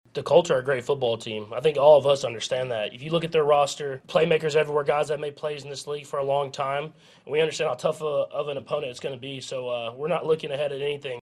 (cut) Chiefs quarterback Patrick Mahomes says that the Colts are a team full of playmakers.